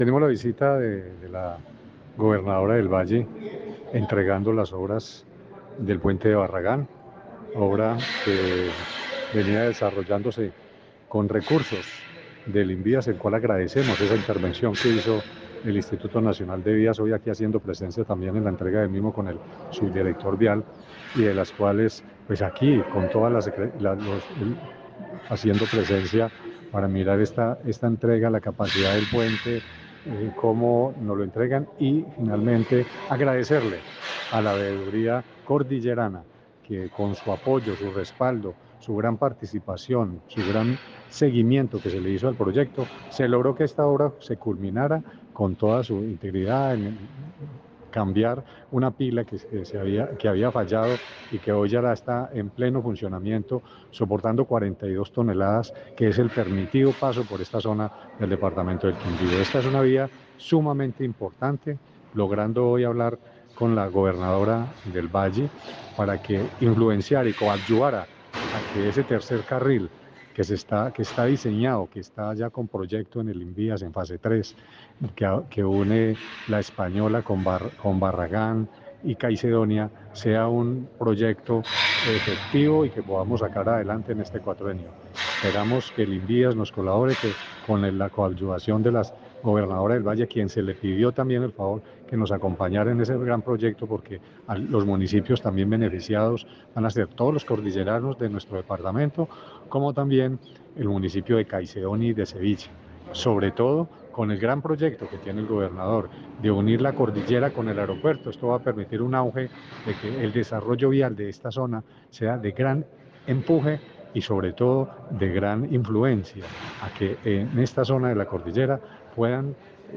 Audio: Rubén Darío Castillo Escobar secretario Infraestructura